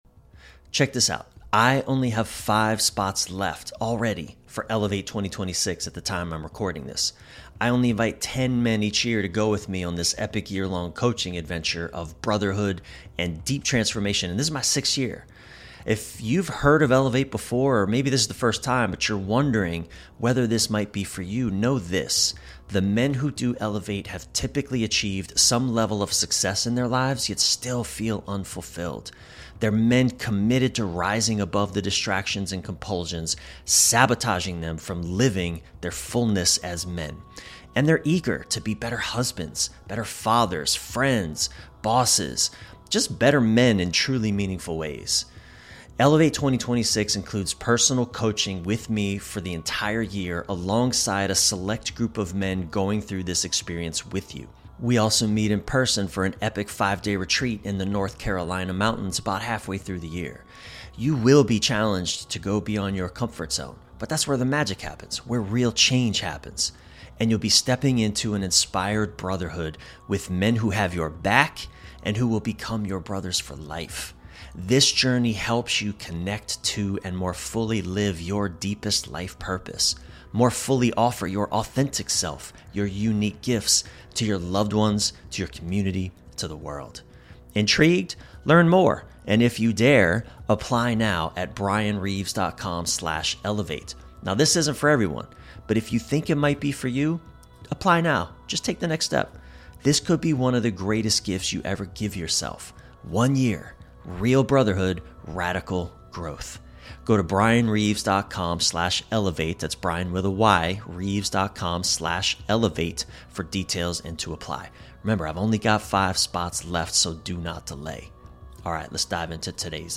In this expansive and very human conversation